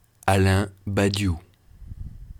pronunciation: FR / French Paris